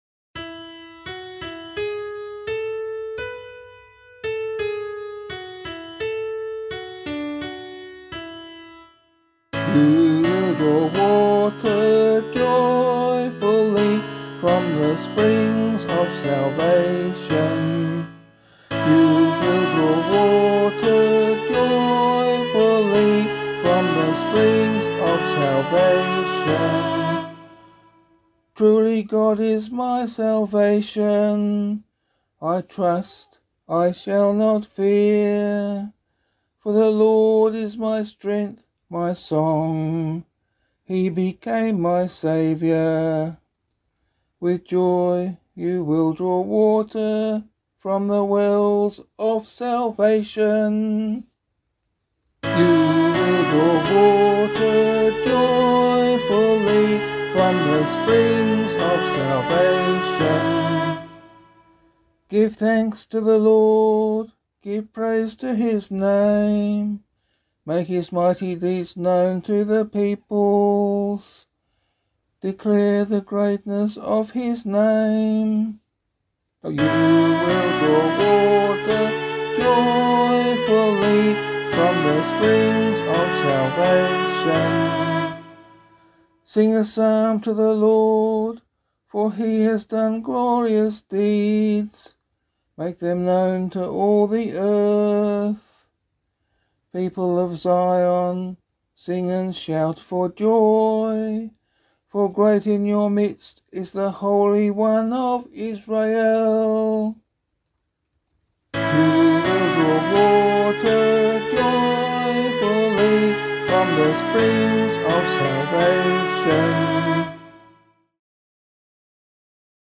022 Easter Vigil Psalm 5 [LiturgyShare 8 - Oz] - vocal.mp3